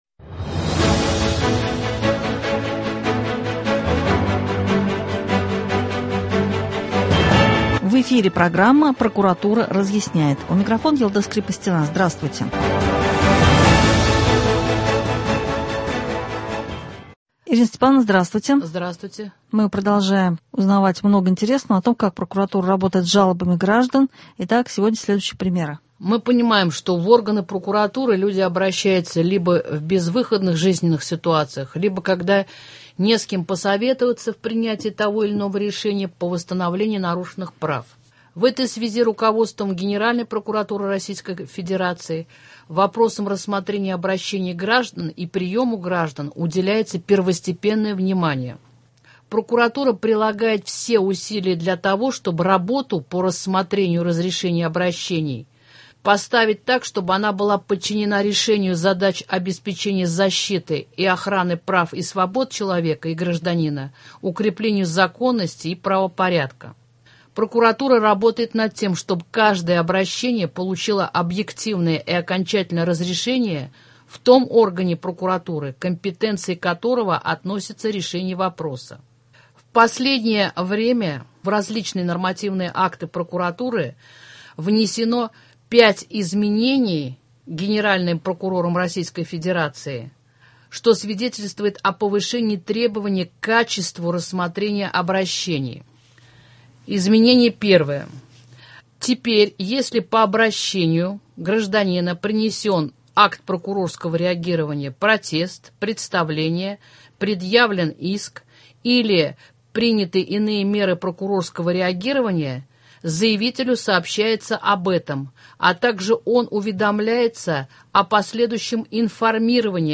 Два раза в месяц представители прокуратуры Республики Татарстан разъясняют: изменения в законах, права граждан на их защиту, также запланирован детальный разбор конкретных ситуаций. В студии